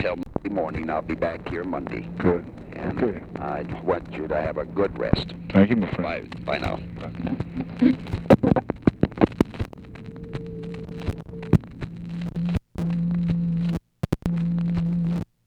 ONLY FINAL PORTION OF CALL RECORDED; HHH'S TRAVEL PLANS; HHH WISHES LBJ A GOOD REST ON EASTER VACATION
Secret White House Tapes